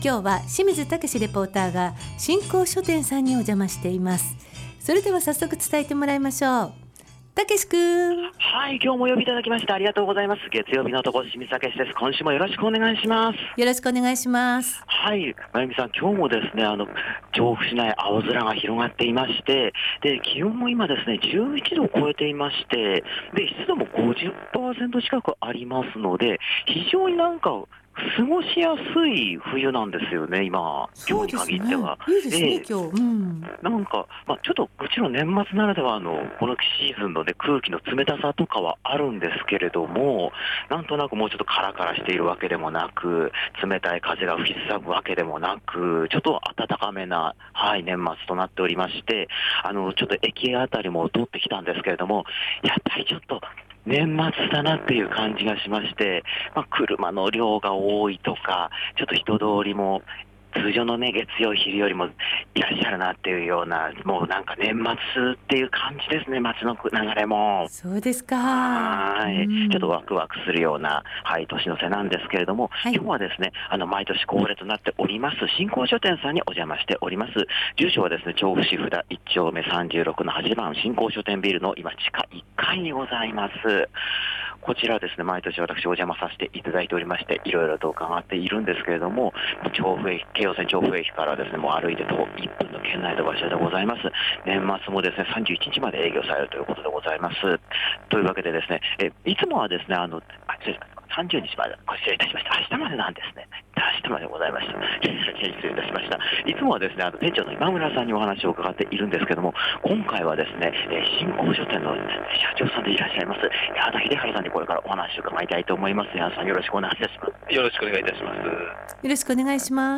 年末らしい雰囲気、青空の下からお届けした本日の街角レポートは、 年末恒例「真光書店」さんからのレポートです！